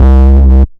Fat Double Bass.wav